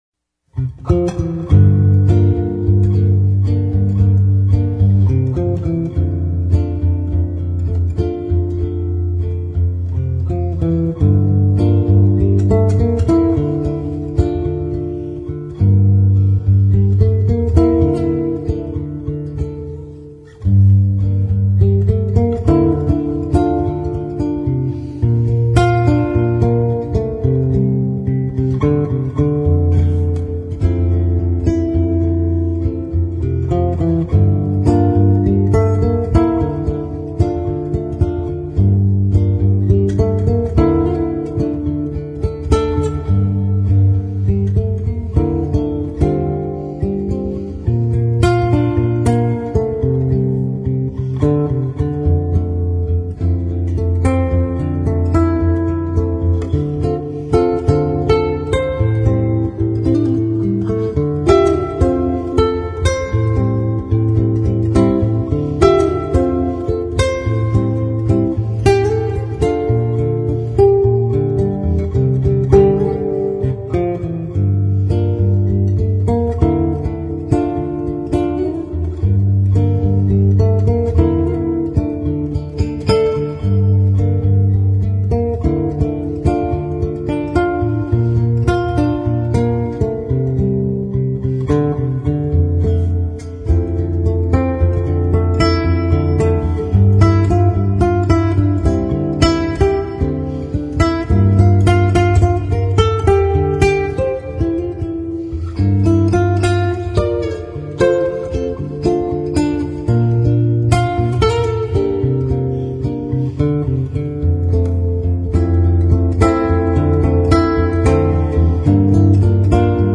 Violao